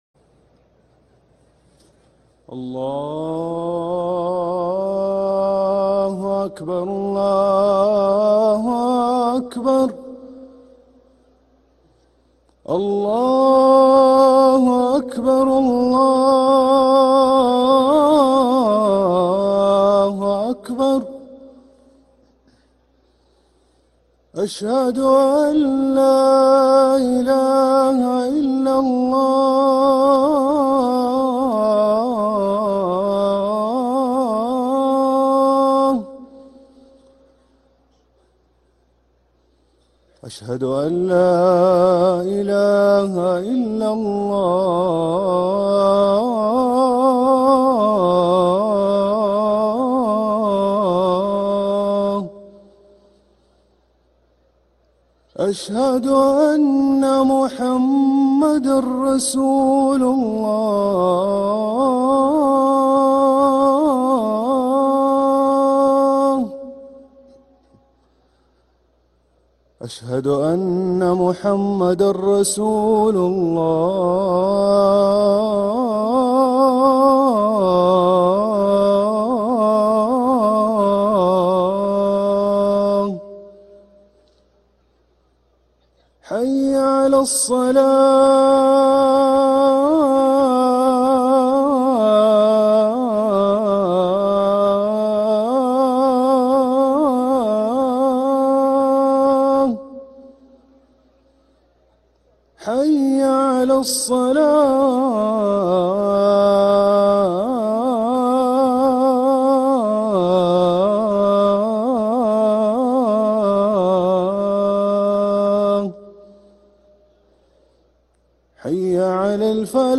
أذان الظهر للمؤذن هاشم السقاف الثلاثاء 5 ربيع الثاني 1446هـ > ١٤٤٦ 🕋 > ركن الأذان 🕋 > المزيد - تلاوات الحرمين